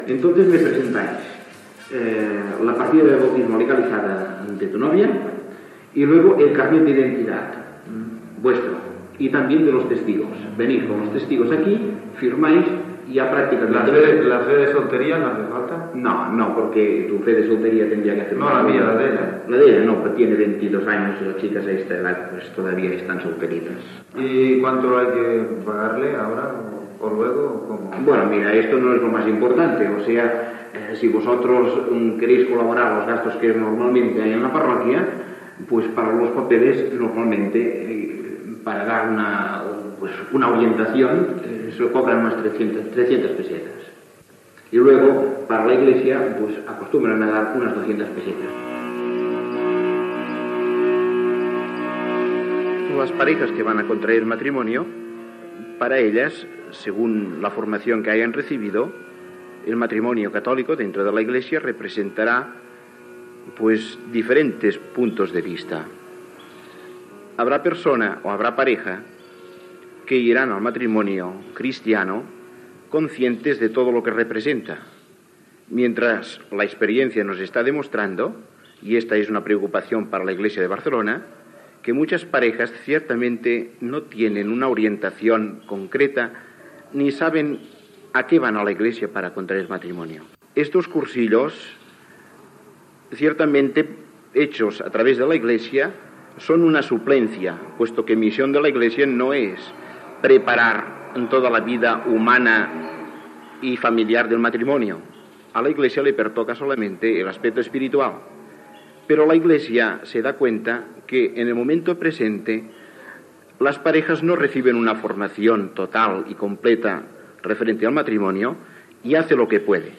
Reportatge sobre els tràmits per casar-se fet a la parròquia de Sant Baptista de Viladecans